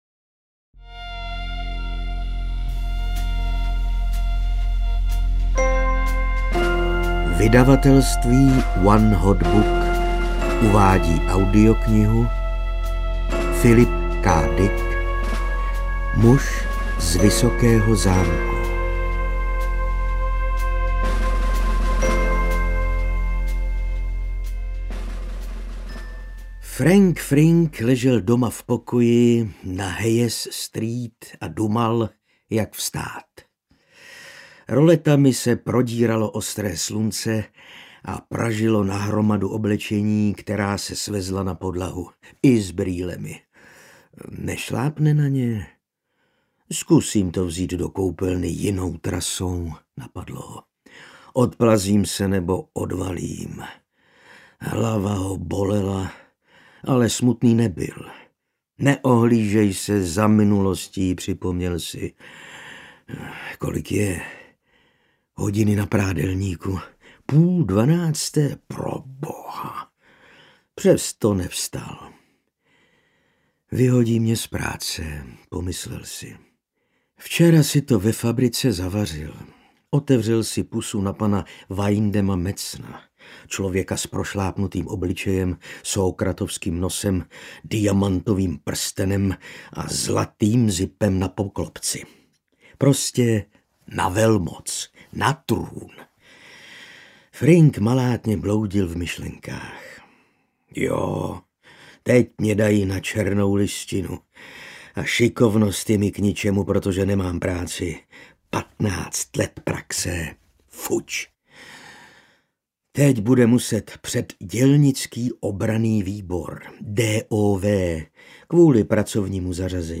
Muž z vysokého zámku audiokniha
Ukázka z knihy